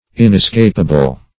Inescapable \In`es*cap"a*ble\, a.